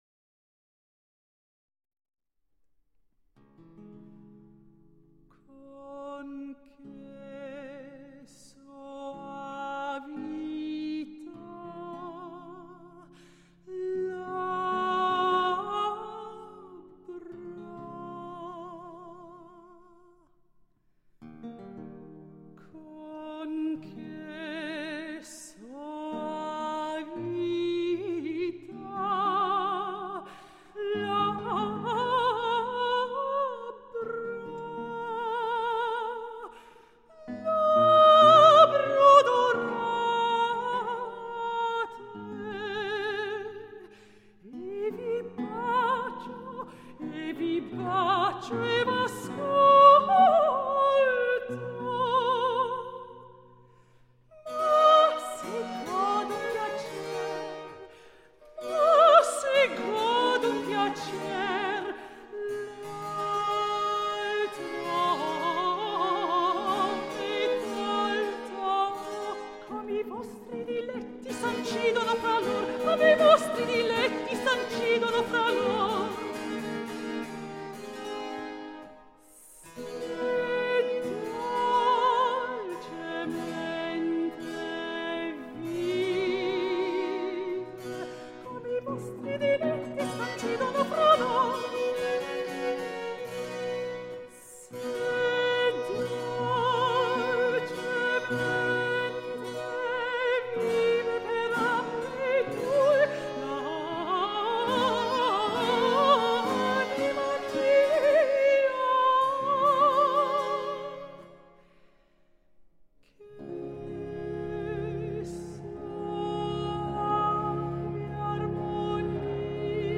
音乐，文字，图片。